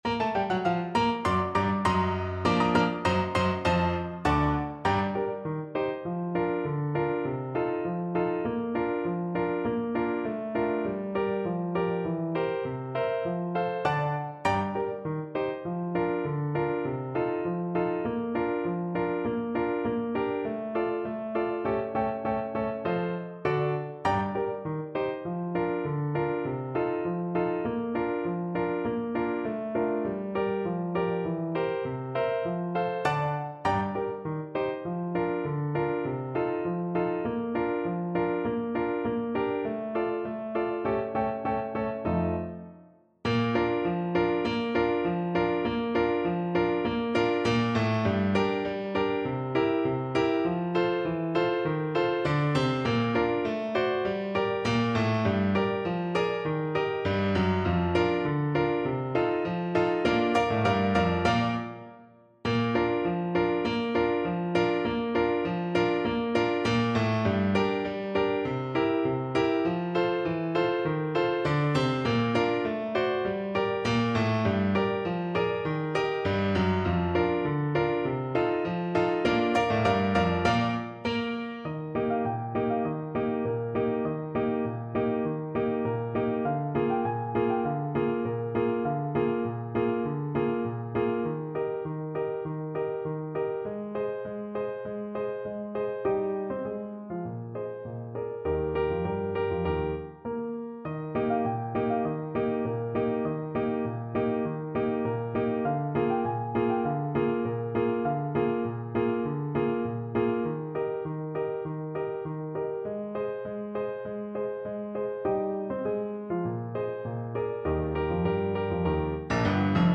2/2 (View more 2/2 Music)
A4-G6
Classical (View more Classical Trumpet Music)